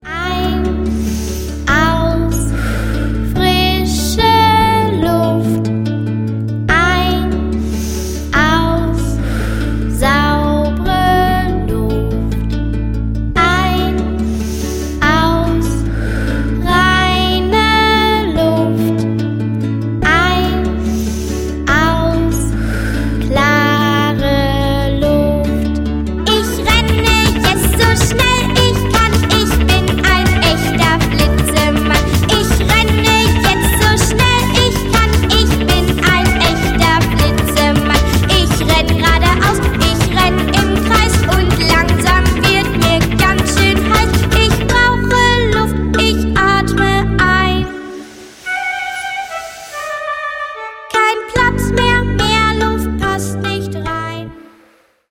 Natur-, Umwelt-, und Klimaschutzlieder